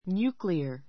nuclear njúːkliə r ニュ ー ク りア